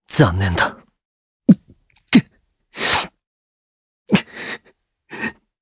Esker-Vox_Sad_jp.wav